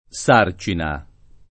vai all'elenco alfabetico delle voci ingrandisci il carattere 100% rimpicciolisci il carattere stampa invia tramite posta elettronica codividi su Facebook sarcina [ S# r © ina ] s. f. (lett. «fardello»; biol. «batterio»)